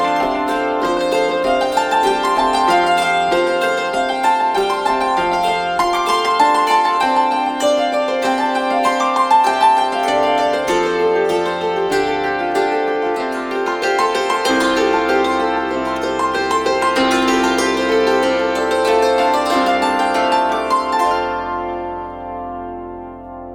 Instrumentalmusik